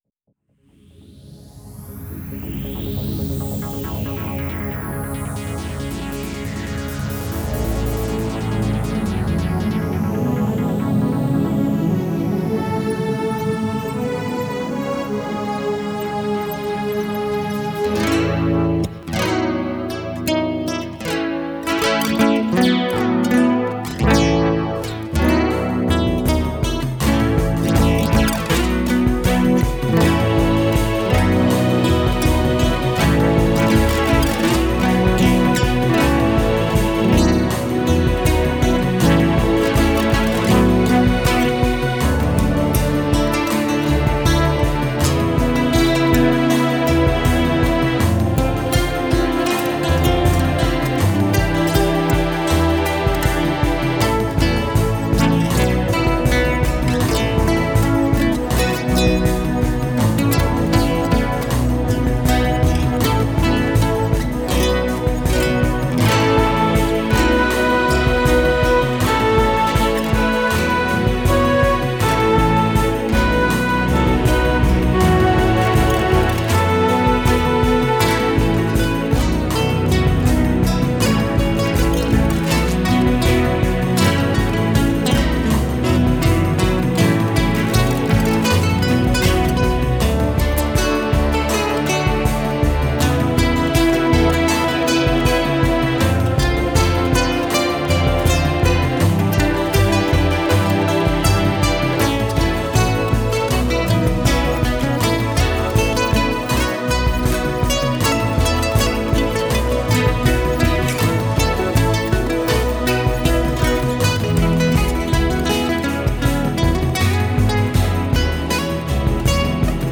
Komplett neu eingespielt in 2014.